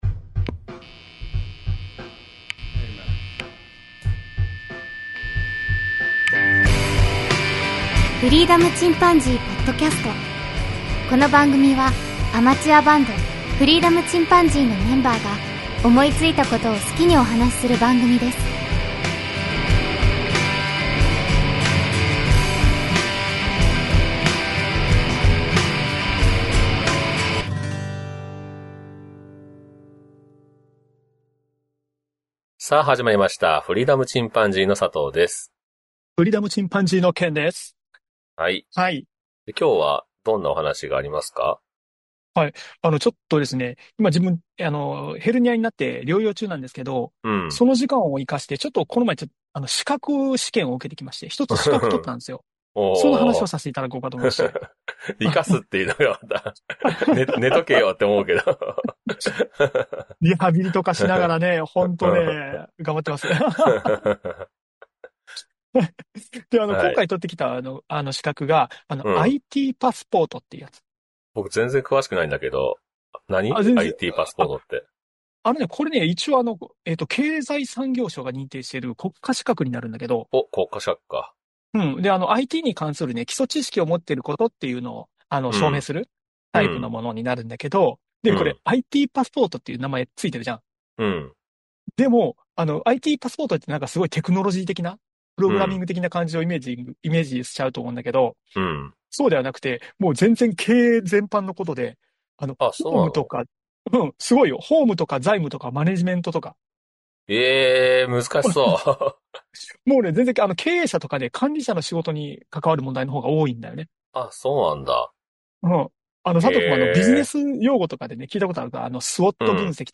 自由気ままなフリートークと、たまにオリジナル音楽をお送りします。